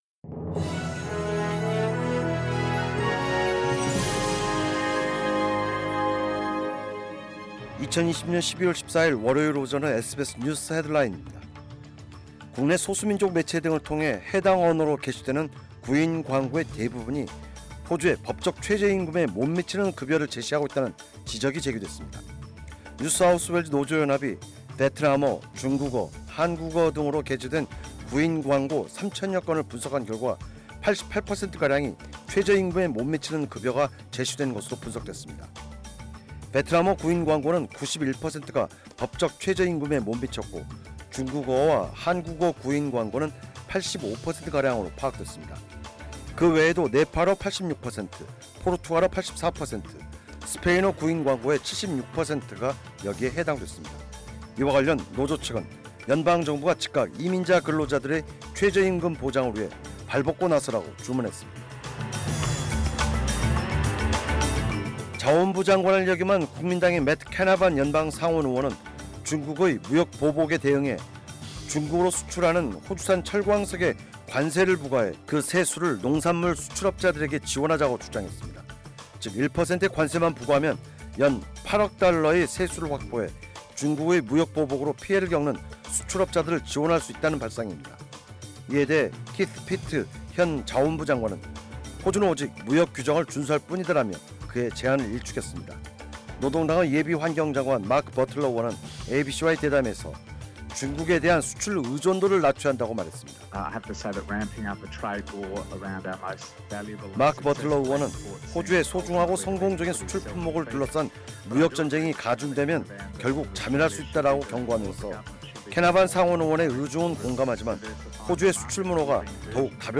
2020년 12월 14일 월요일 오전의 SBS 뉴스 헤드라인입니다.